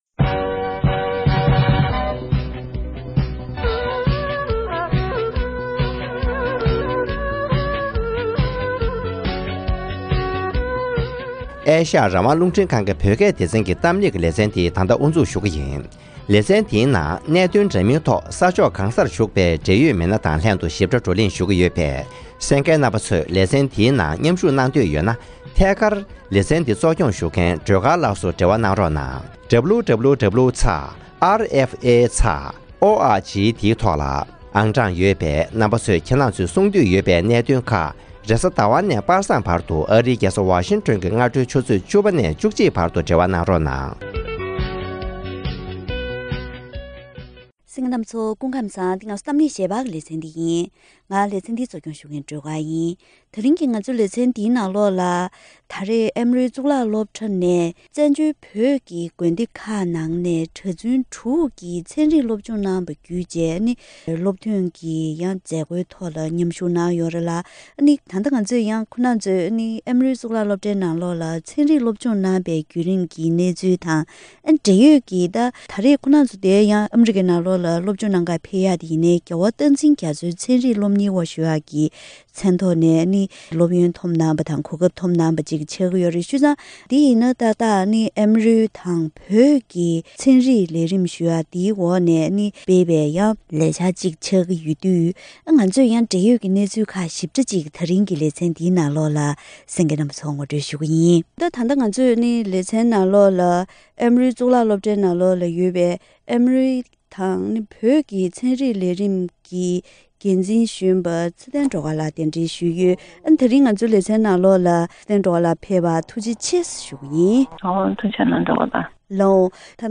ཁོང་རྣམ་པས་ནུབ་ཕྱོགས་ཀྱི་གཙུག་ལག་སློབ་གྲྭ་ཆེན་མོའི་ནང་ཚན་རིག་སློབ་སྦྱོང་གནང་བའི་ཉམས་མྱོང་སོགས་འབྲེལ་ཡོད་སྐོར་ལ་བཀའ་མོལ་ཞིབ་ཕྲ་ཞུས་པ་ཞིག་གསན་རོགས་་གནང་།